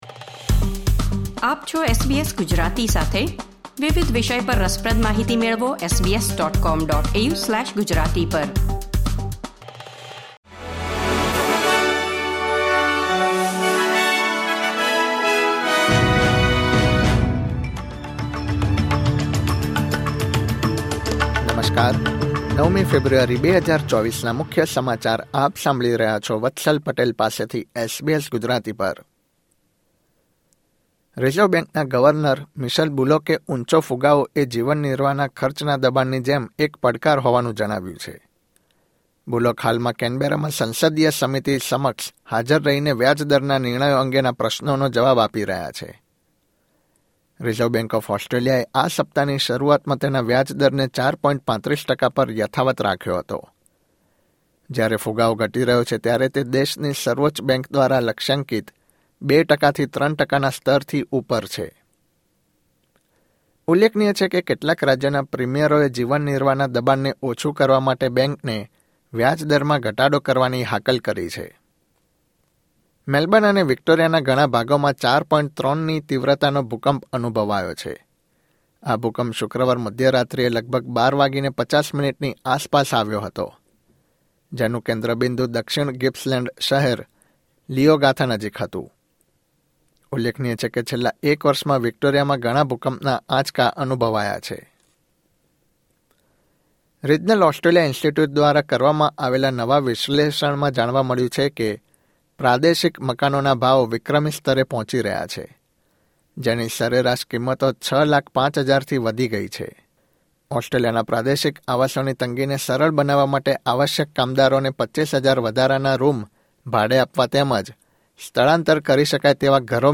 SBS Gujarati News Bulletin 9 February 2024